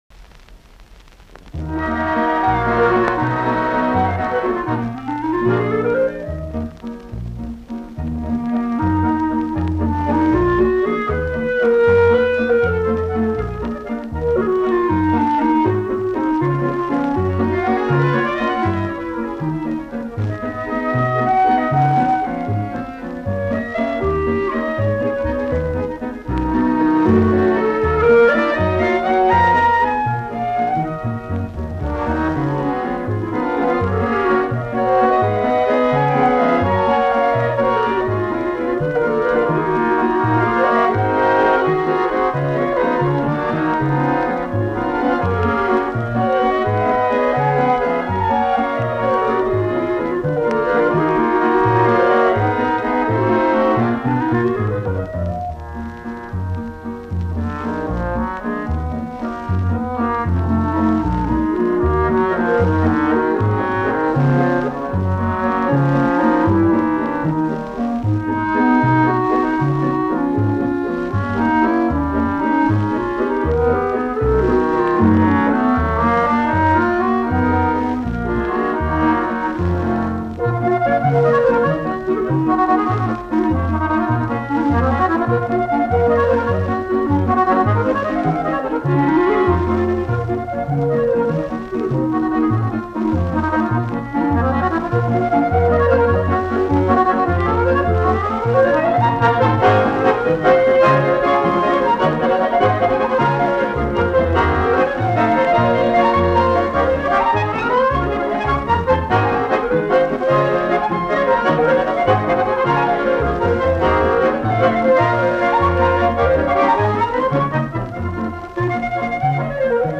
вальс